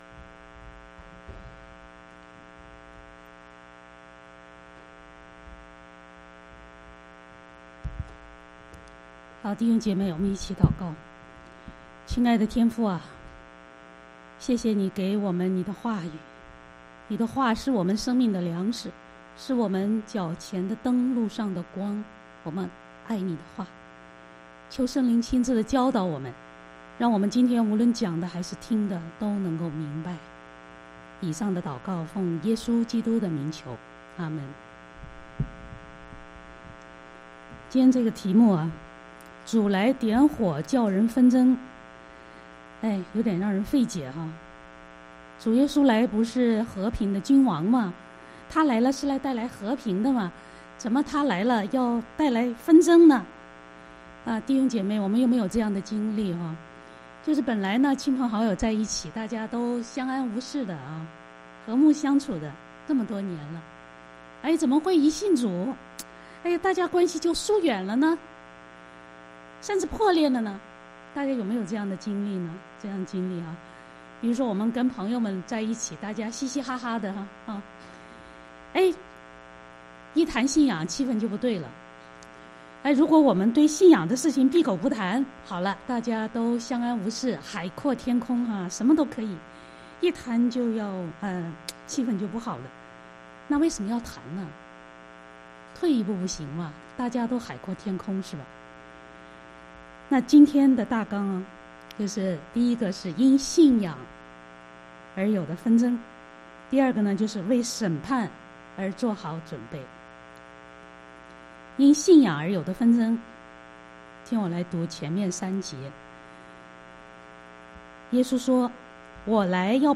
sermon [烈治文山国语宣道会 Richmond Hill Mandarin Alliance Church]